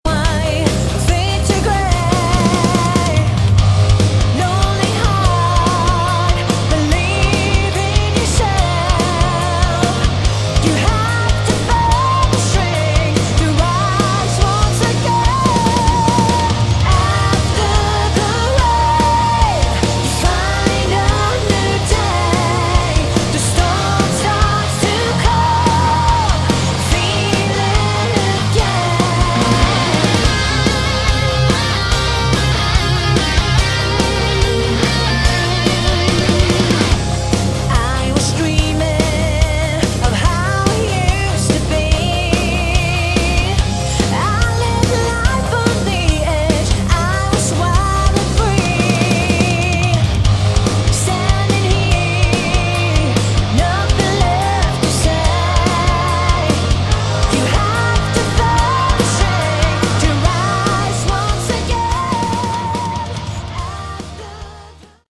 Category: Melodic Rock
vocals
guitars
bass
keyboards
drums